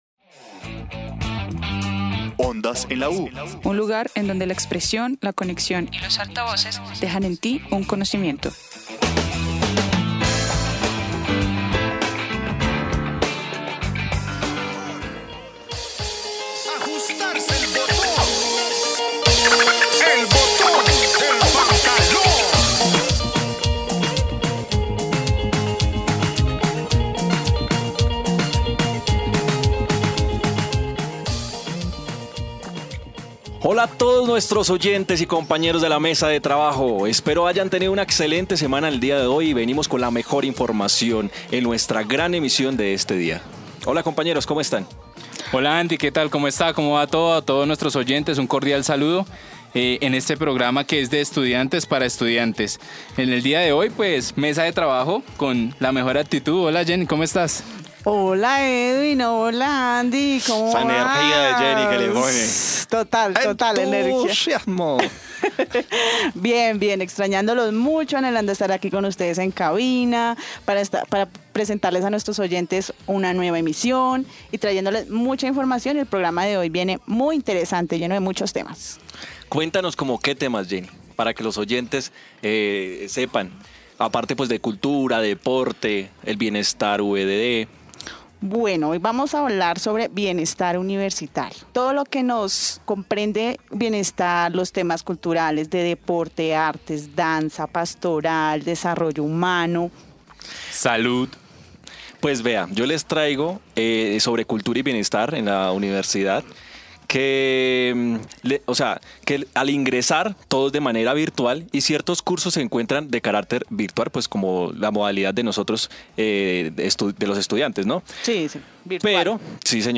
Como temas más notables fueron las incapacidades médicas, y el apoyo económico que brinda la universidad. Escucharán tres track diferentes, con motivo de dar alusión a la salsa, la danza, el movimiento, la espiritualidad y agradecimiento a Dios.